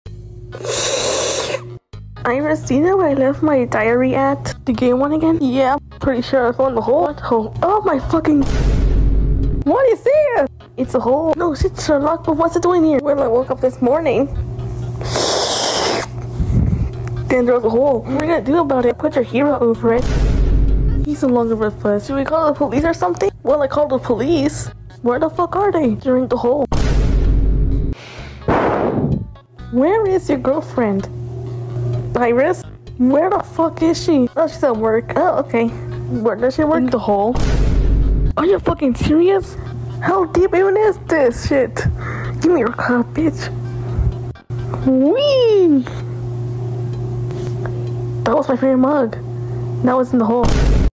the hole but i voiceover it very disgustingly